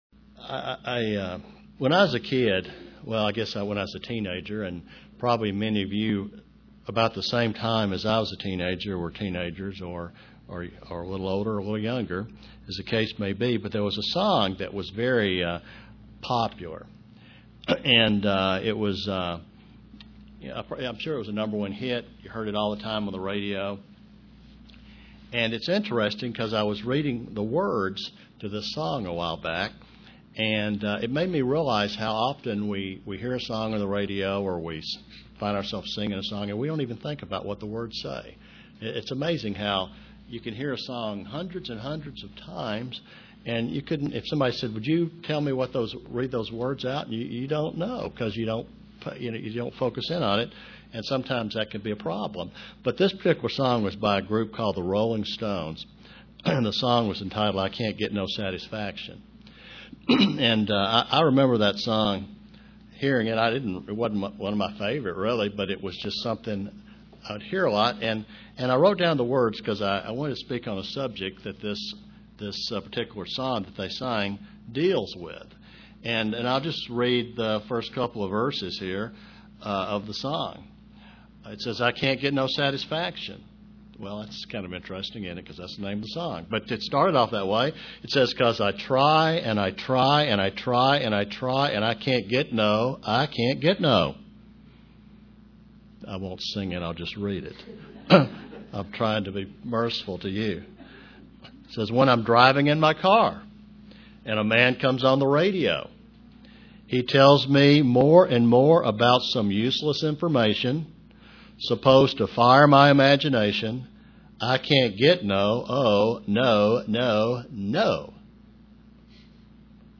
Print Being content with our life is a great joy UCG Sermon Studying the bible?